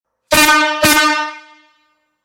Buzinas a Ar para Bicicletas Jumbo
• 01 corneta;
• Intensidade sonora 130db;
• Acionamento através de bomba manual;
Som da buzina